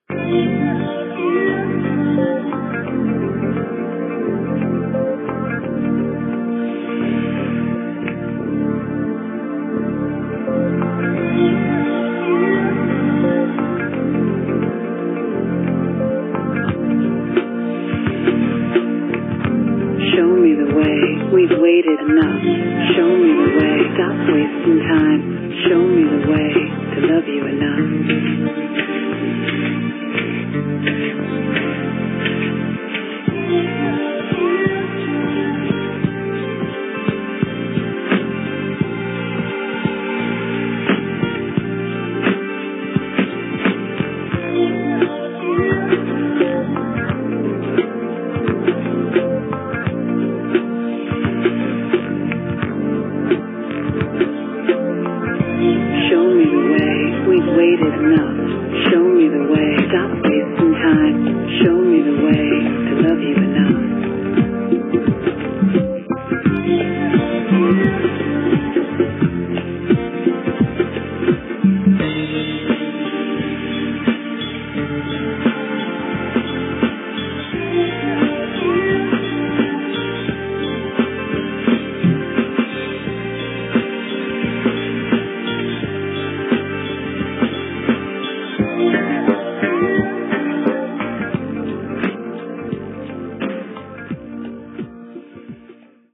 Sintonia de tancament de la ràdio, a la nit